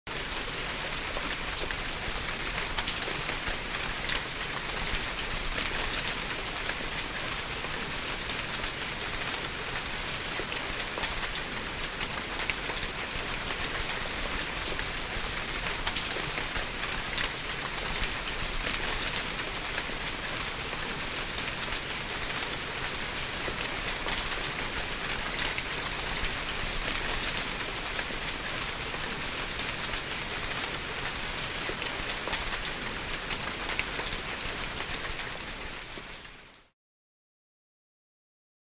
Все дожди записаны без грома, это просто дожди, которые можно скачать для звукового оформления любого контента. Качество записей высокое.
Однотонный, нудный, с каплями на переднем плане — 38 сек